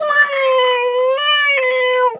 pgs/Assets/Audio/Animal_Impersonations/cat_2_meow_long_01.wav at master
cat_2_meow_long_01.wav